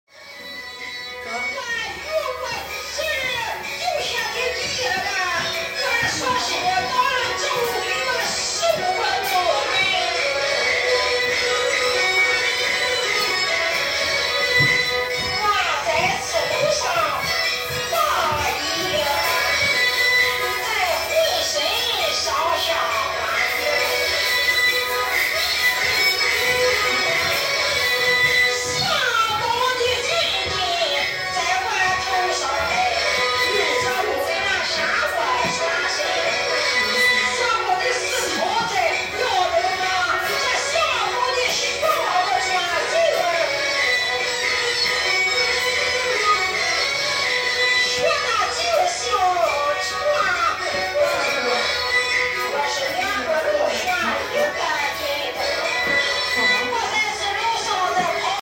Although scenes of faith have vanished in Qingdao's A-Ma Temple, this recording captures the resonance of local music, now echoing within the temple walls as part of a folklore museum and documents visitors to the A-Ma Temple, unfamiliar with A-Ma's beliefs, seeking information from their companions. The historical journey of A-Ma's beliefs parallels the migrations of the Minnan people, shaping the coastal regions' cultural landscape in China.
Part of the Migration Sounds project, the world’s first collection of the sounds of human migration.